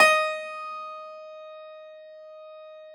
53f-pno15-D3.aif